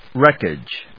wreck・age /rékɪdʒ/